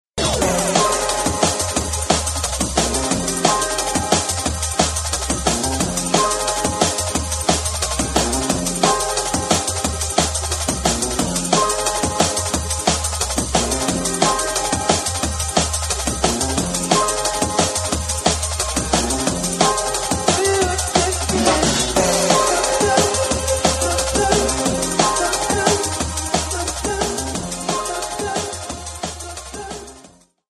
TOP > Jump Up / Drum Step